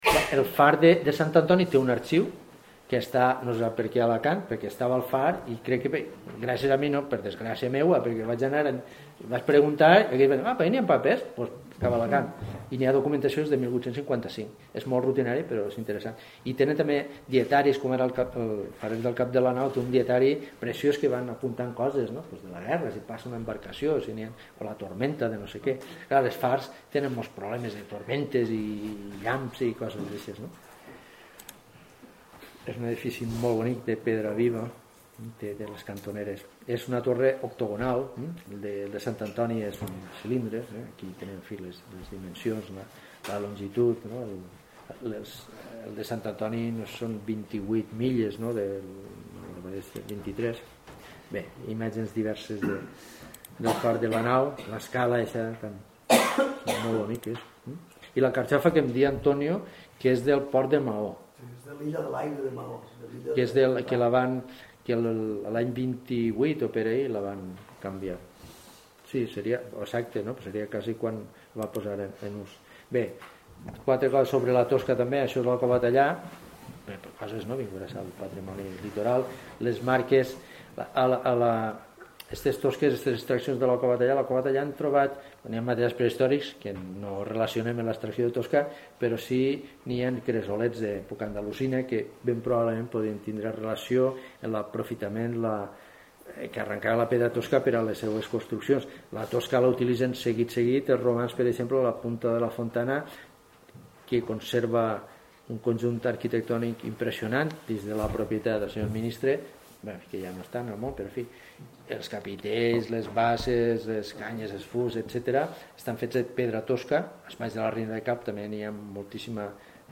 El divendres dia 12 de febrer al Museu etnològic Soler Blasco es va celebrar la segona conferència de les I Jornades IROX :